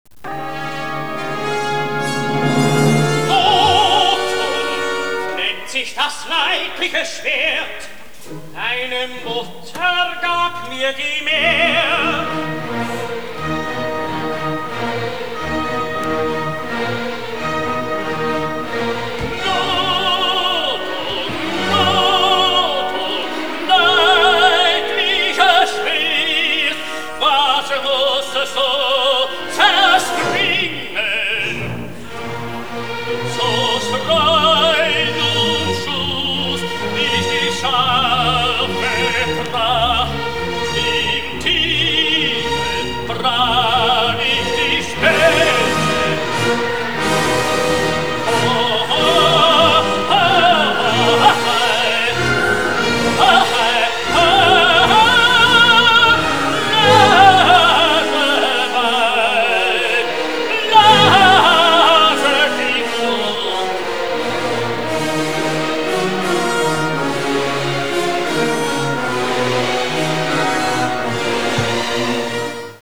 Verweilen wir noch kurz beim Schmied, schauen wir zu wie Siegfried sein Schwert Notung schmiedet (in der Oper zum Beispiel).
Es wird heiß gemacht, er klopft unter Gesang mit einem Hammer darauf herum ("